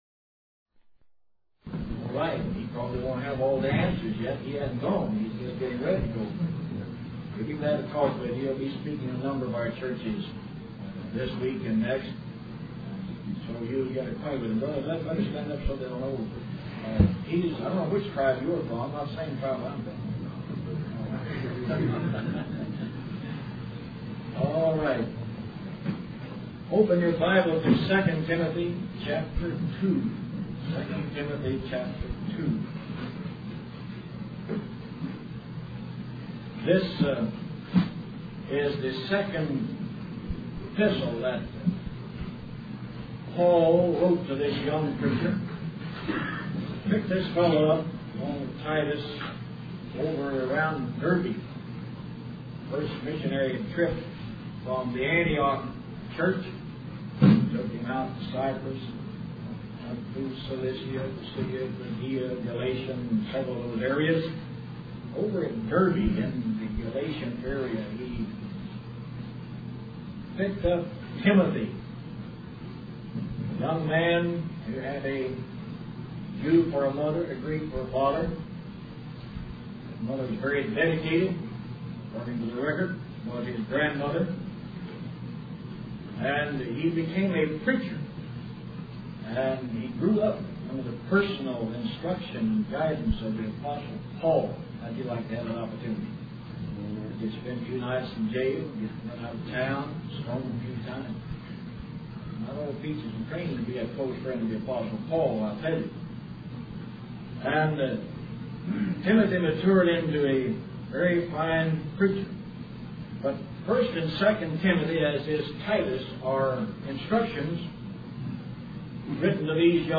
The Call To the Ministry This message was preached at the CMBI Chapel service January 20, 1976.